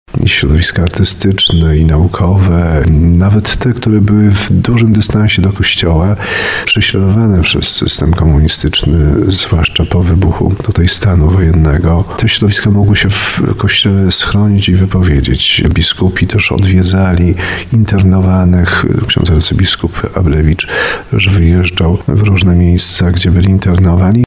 13 grudnia mijają 44 lata od ogłoszenia przez władze komunistyczne stanu wojennego w Polsce. Biskup tarnowski Andrzej Jeż podkreśla, że Kościół był w tym okresie szczególnym wsparciem dla prześladowanych reżimem komunistycznym.